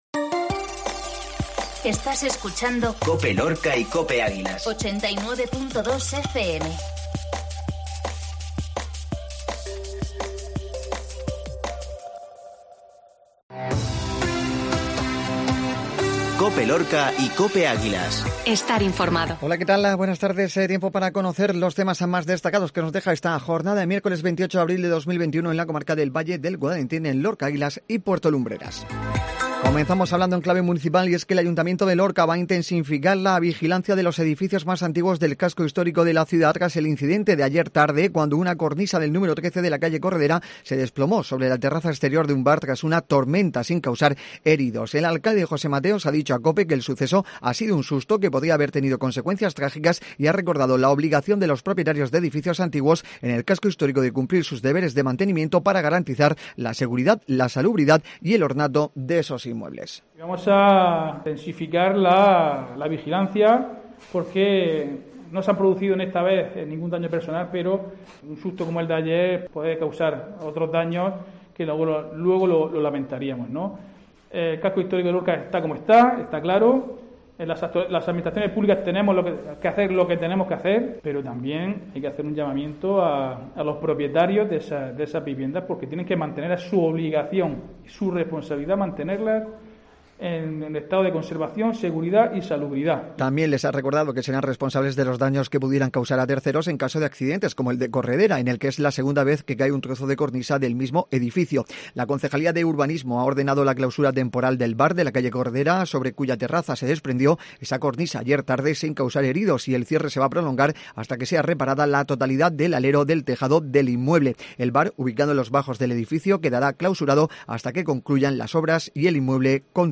INFORMATIVO MEDIODÍA MIÉRCOLES